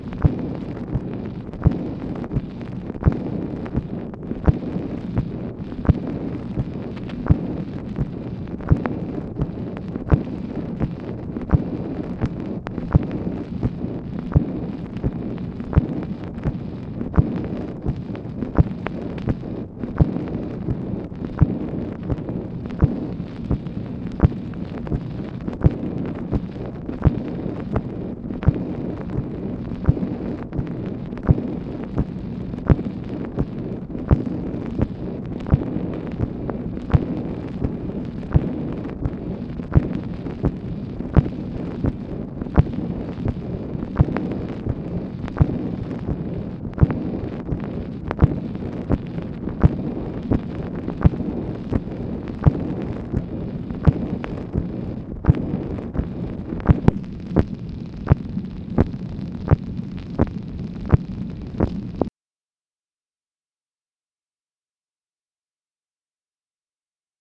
MACHINE NOISE, June 30, 1965
Secret White House Tapes